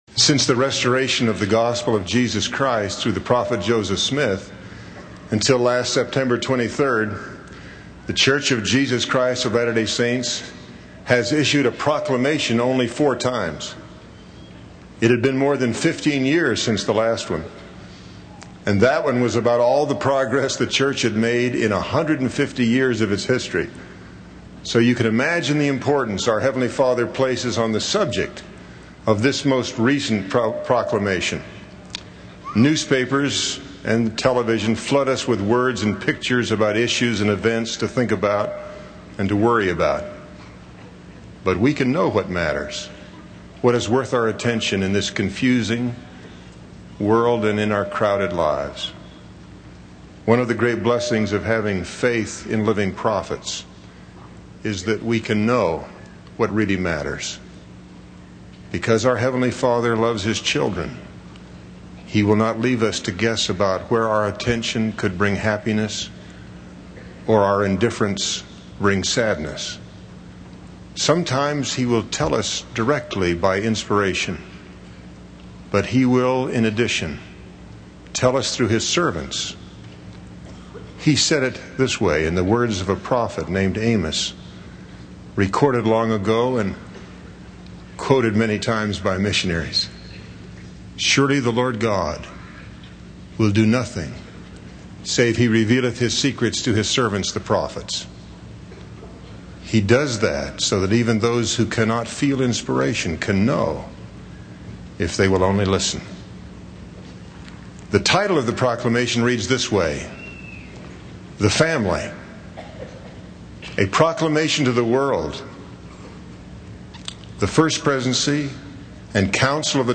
Audio recording of The Family by Henry B. Eyring